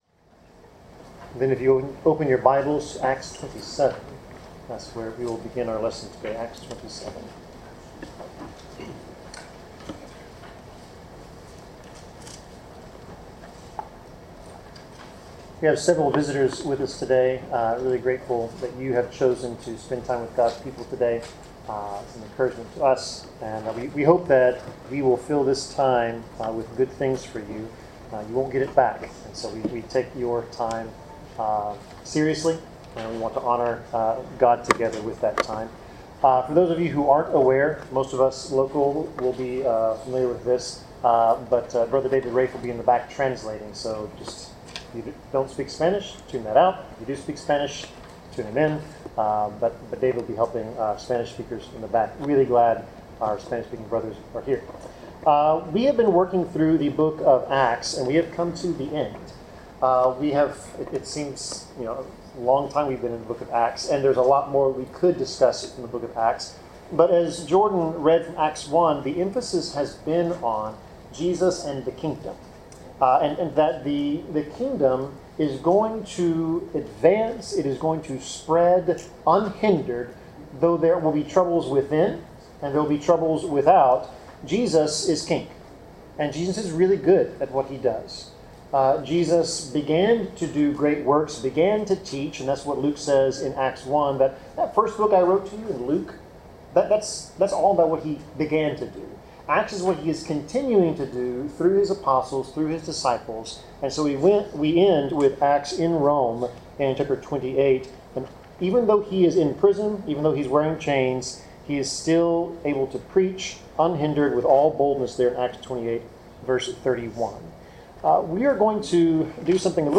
Passage: Acts 27-28 Service Type: Sermon